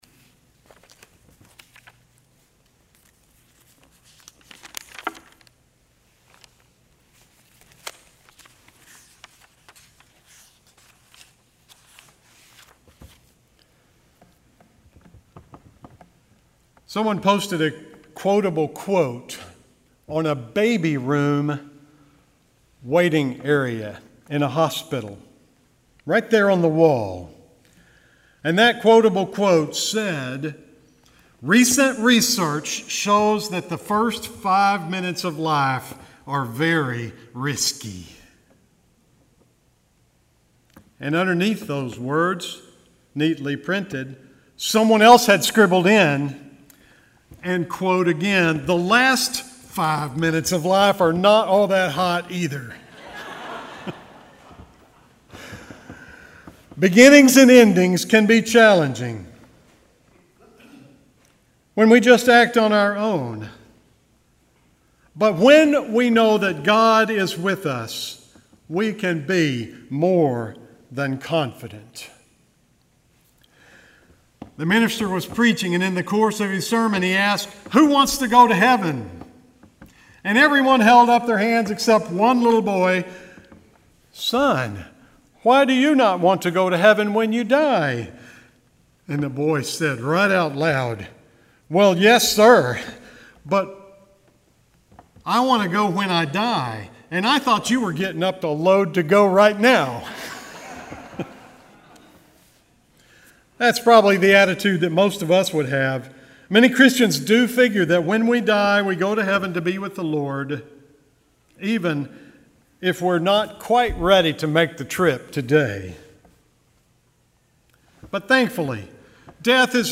April 21, 2019 Sermon